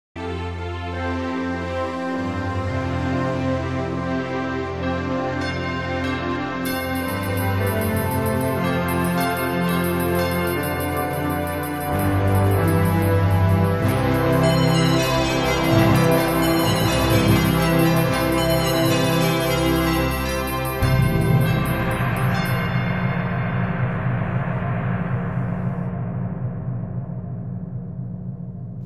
the mood turns to melancholy as we remember the Ripper's